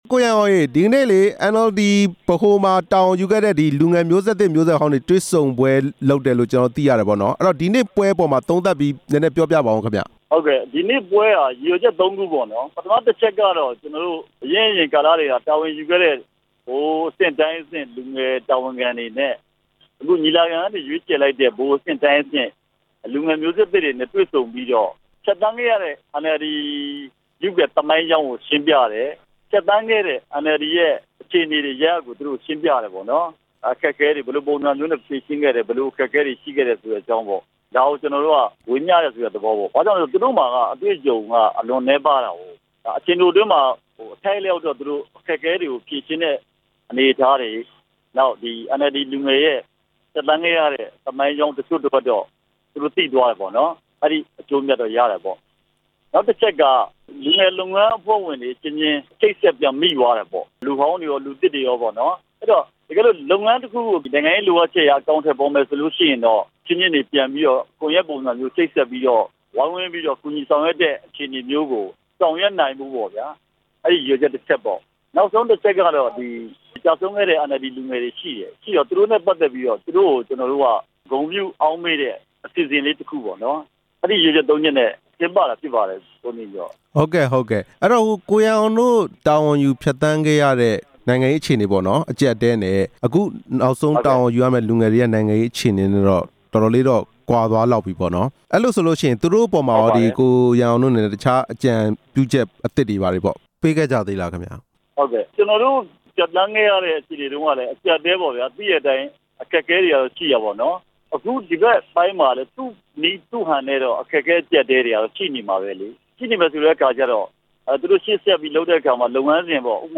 အမျိုးသားဒီမိုကရေစီအဖွဲ့ချုပ်မှာ တာဝန်ယူခဲ့ကြတဲ့ လူလတ်ပိုင်းနဲ့ လူငယ်မျိုးဆက်တွေရဲ့ တွေ့ဆုံပွဲကို ဒီနေ့ရန်ကုန်မြို့ ရွှေဂုံတိုင်က တော်ဝင်းနှင်းဆီခန်းမမှာ ကျင်းပရာမှာ ဒေါ်အောင်ဆန်းစုကြည်က အဲဒီလို တိုက်တွန်း ပြောကြားခဲ့တာဖြစ်ပါတယ်။